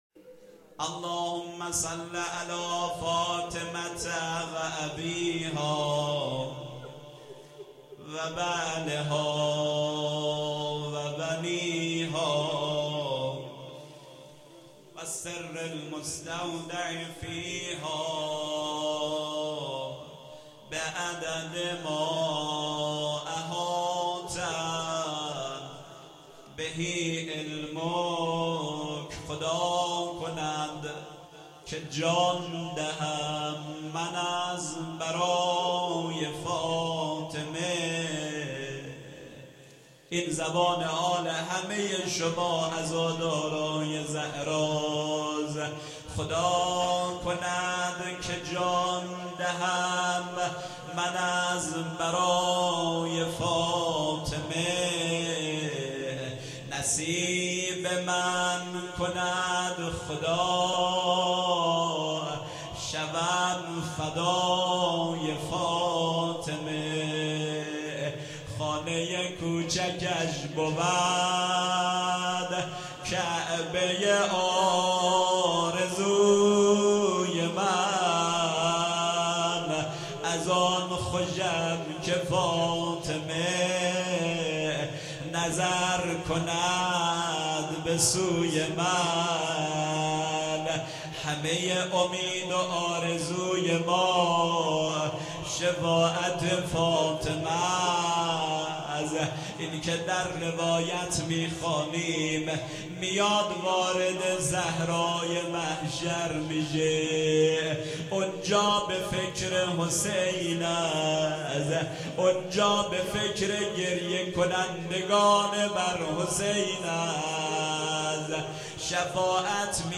در هیئت الرضا هندیجان